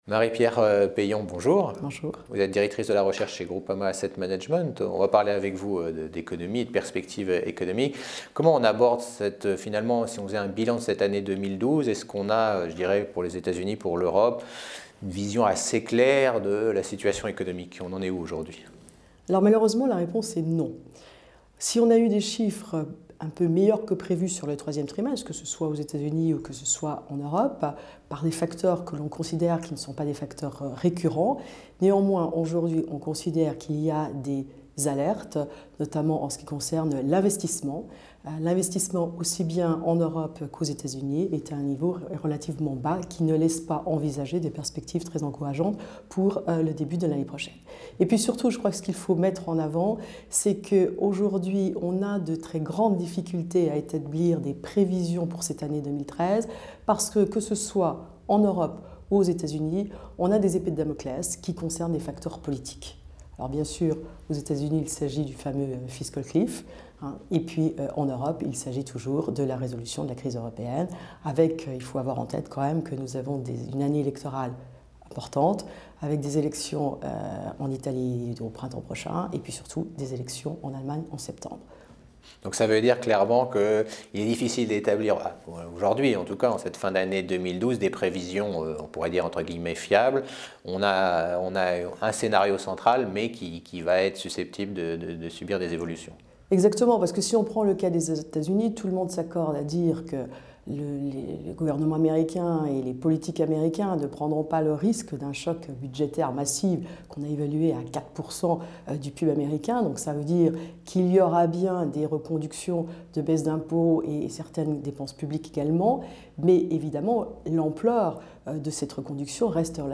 Economie : Interview